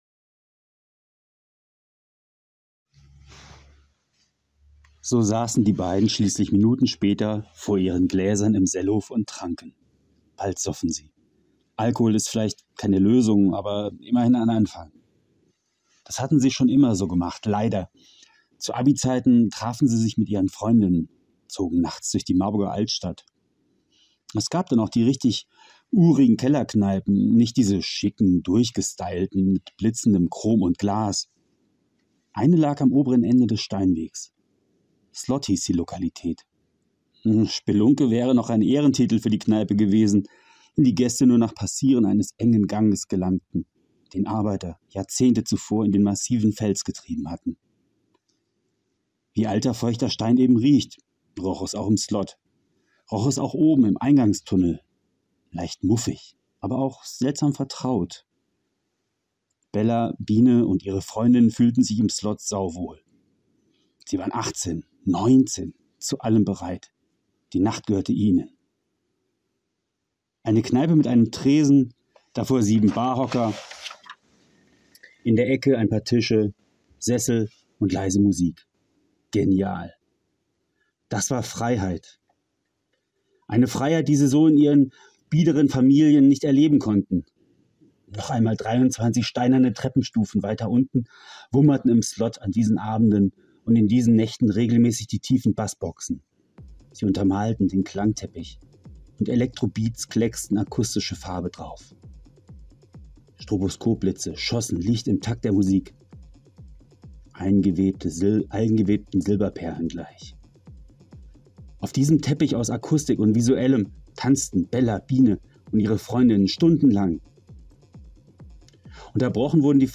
Vorgelesen aus der Krimikomödie "Kopfjagd am Ententeich"
Lesung-Slot-Langfassung(1).mp3